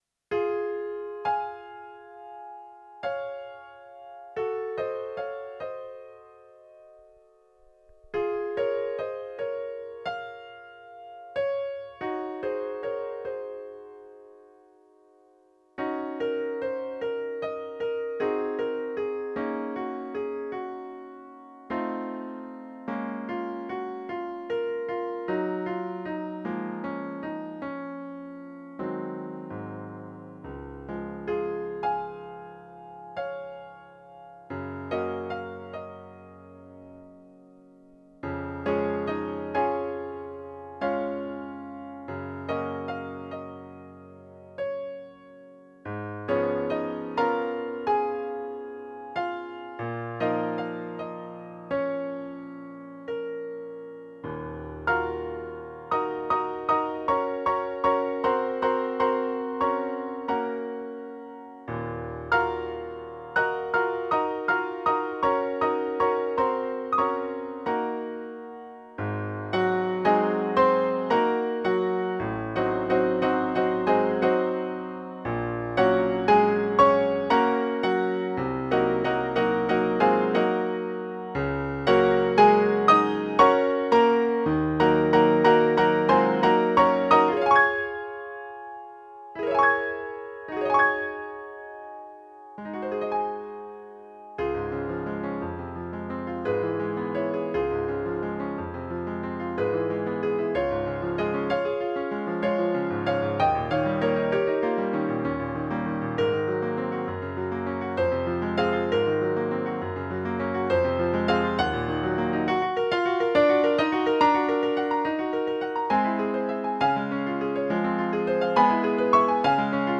Effectivement, je viens d'essayer mon piano numérique (Yamaha CLP150 de 2002... autant dire obsolète), cela sonne plat :